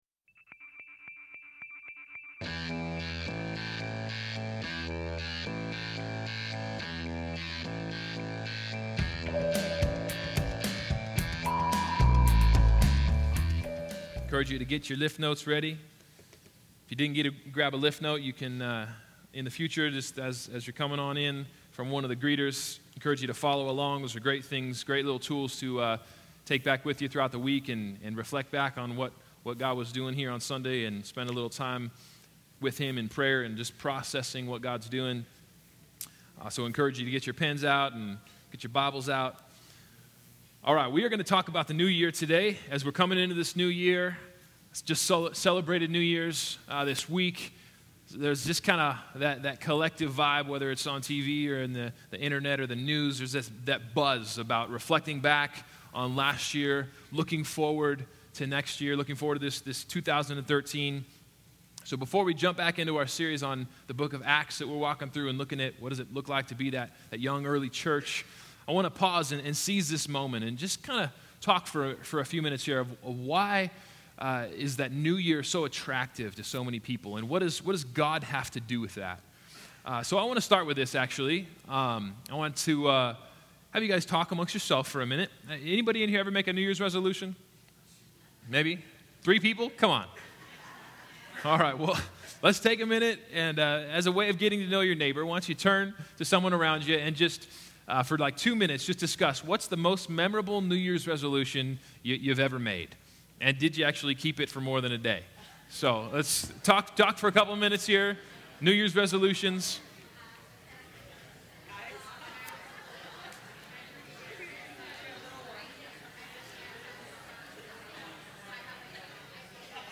The first message of 2013 focuses on how God is in the business of NEW. We are a new creation, made new everyday.